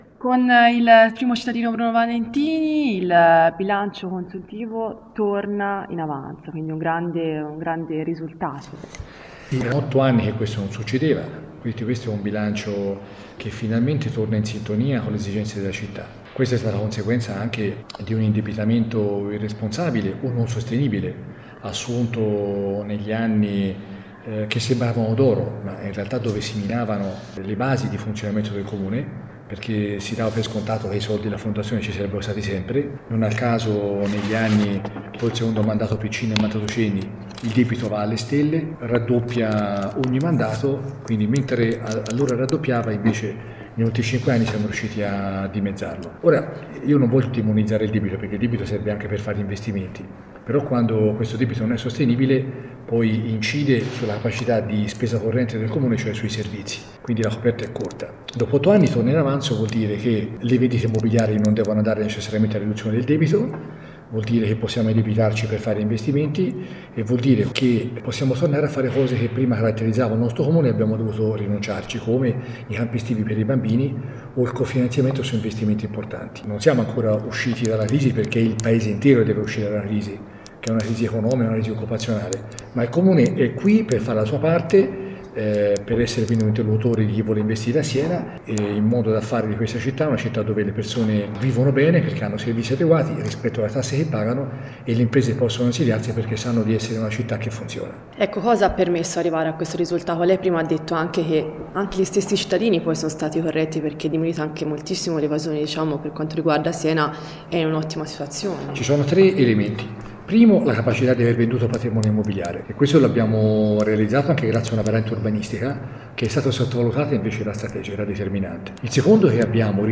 Ascolta l’intervista del sindaco Valentini